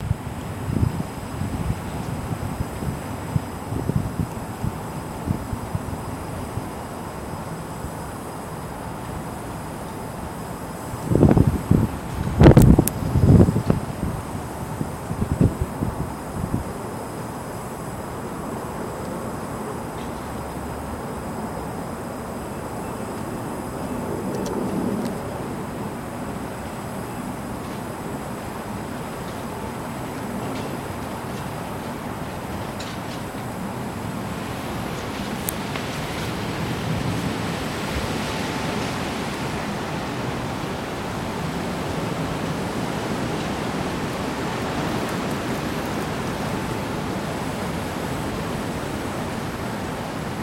Field Recording, week 6
Location: Front steps of Lowe, 9:40am, Thursday, March 10th.
Sounds: Wind blowing near, rustling of a bush, distant wind. There are some small clicks from when I shifted my wight. There’s also a sort of ambient noise throughout.
outside.mp3